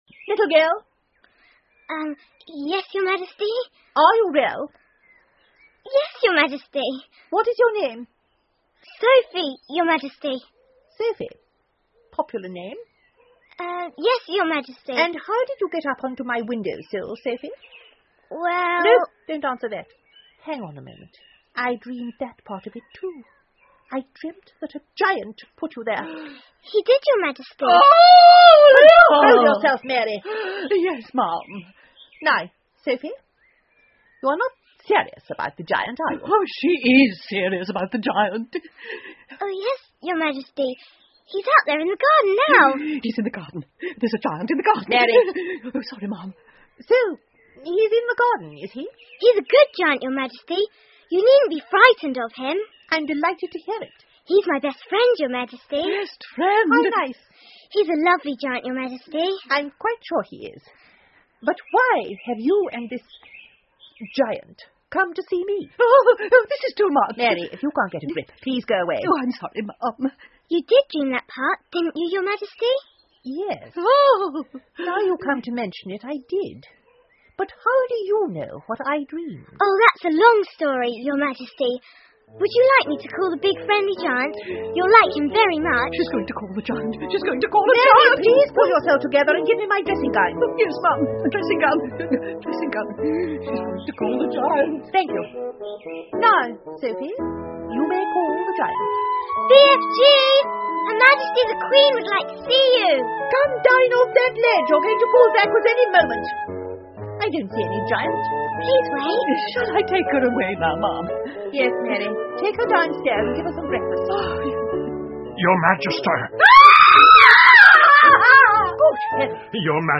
The BFG 好心眼儿巨人 儿童广播剧 14 听力文件下载—在线英语听力室